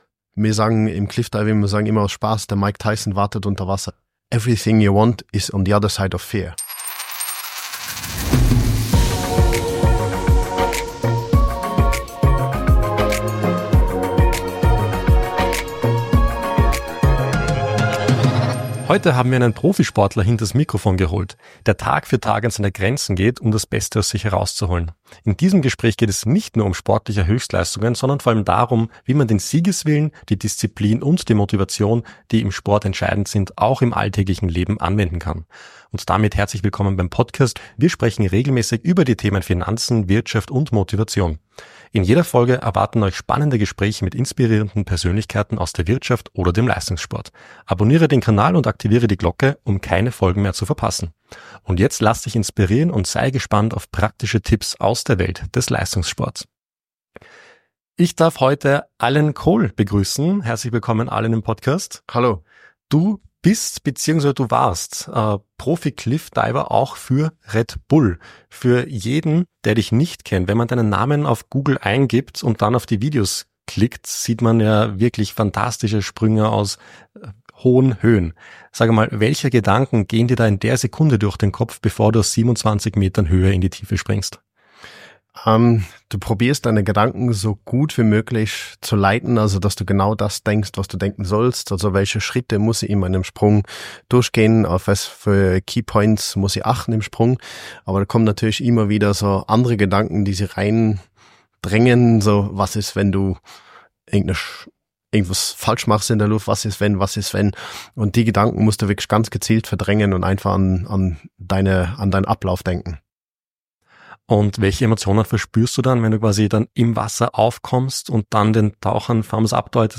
Ein Gespräch voller Inspiration, Ehrlichkeit und beeindruckender Geschichten!